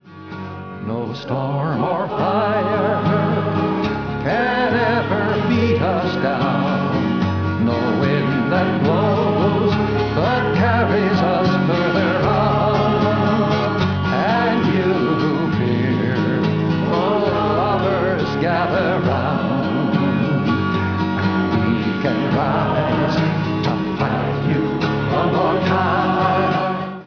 voice, 12-string guitar
chorus